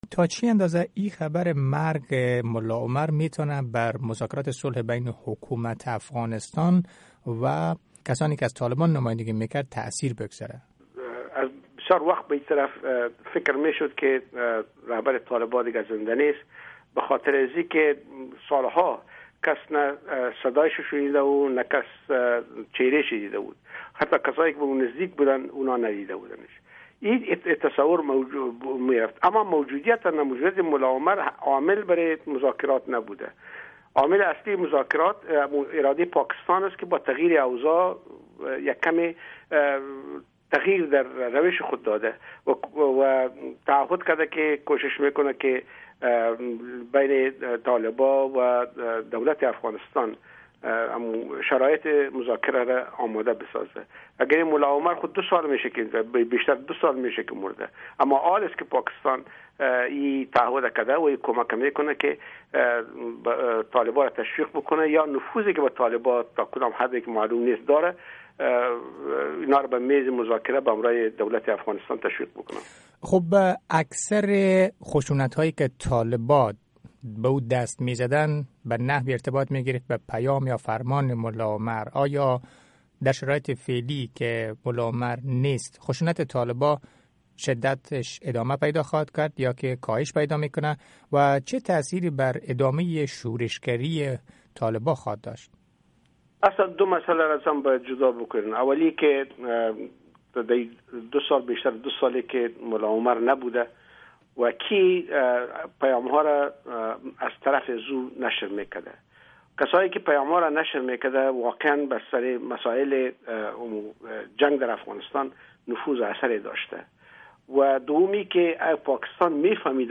مصاحبه ها
آقای جلالی در مصاحبۀ ویژه با رادیو صدای امریکا در مورد تاثیر مرگ ملا عمر بر مذاکرات صلح بین حکومت افغانستان و طالبان و همچنین درمورد چگونگی تغییر سیاست حکومت امریکا در قبال طالبان صحبت کرده است.
مصاحبه با علی احمد جلالی، استاد پوهنتون دفاع ملی امریکا